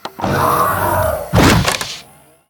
liftdooropen.ogg